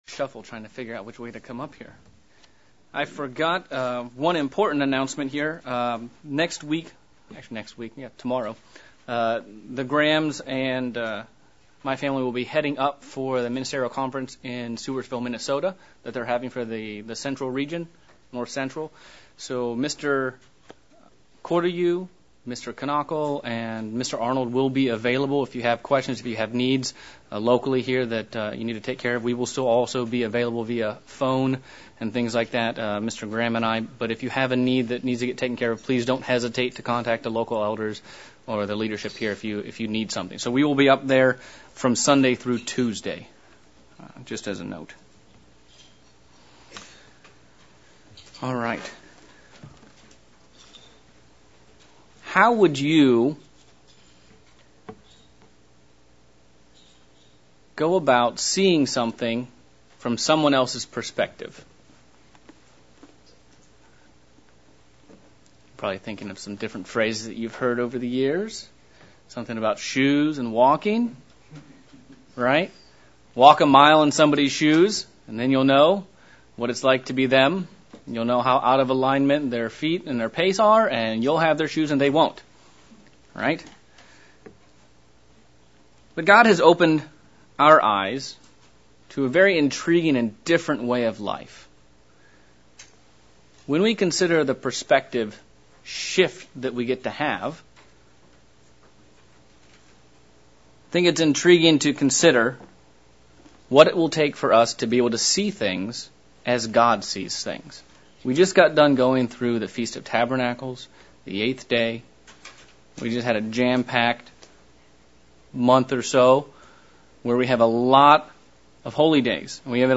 This sermon looks at how to see things as God sees them. It dives in to scriptures to juxtapose the perspective of God versus the perspective of man.